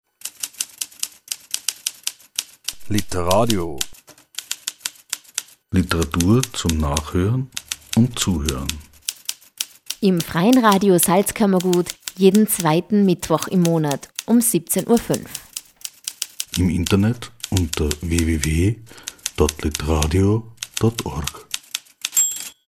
Sendungstrailer
FRS-TRAILER-LITERADIO-2-MITTWOCH.mp3